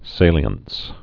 (sālē-əns, sālyəns) also sa·li·en·cy (sālē-ən-sē, sālyən-)